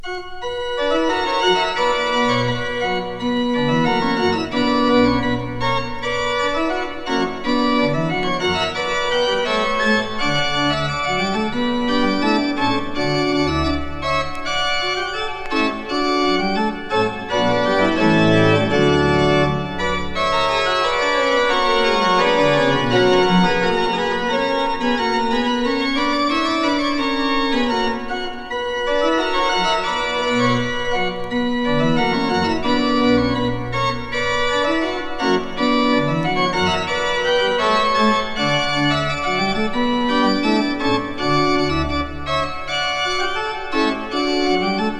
Classical, Baroque　France　12inchレコード　33rpm　Mono